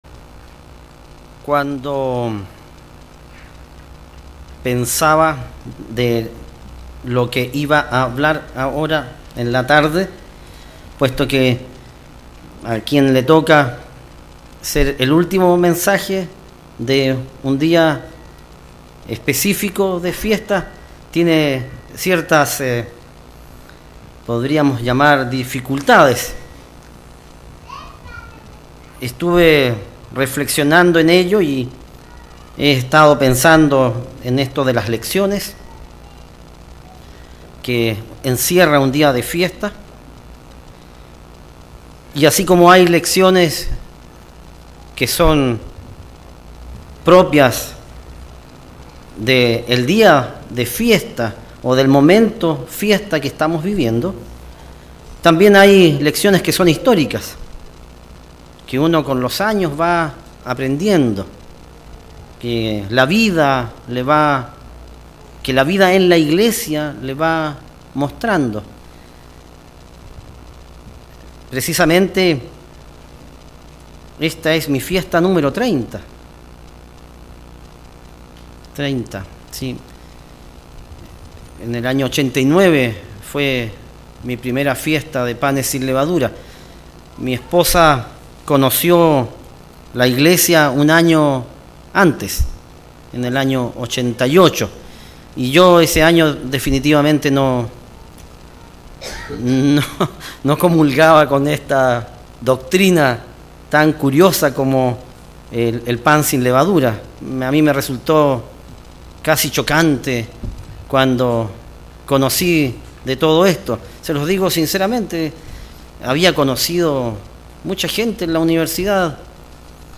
Mensaje entregado el 6 de abril de 2018